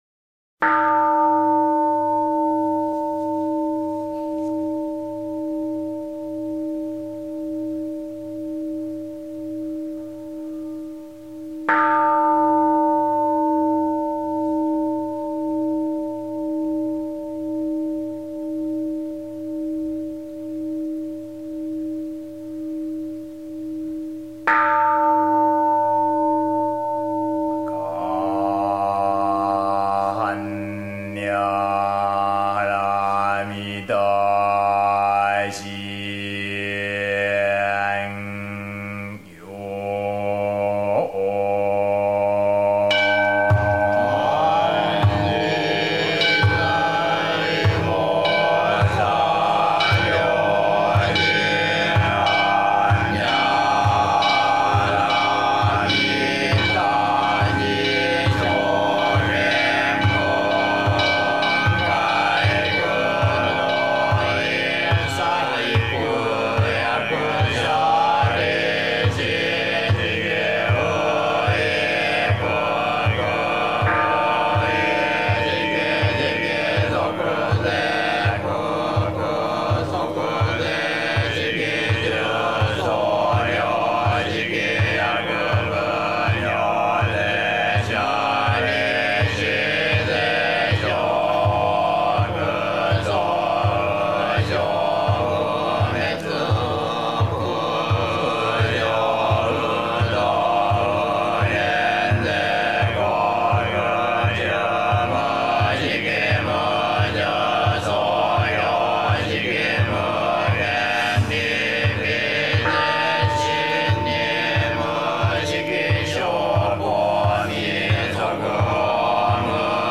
讀經
般若心經 音聲データの再生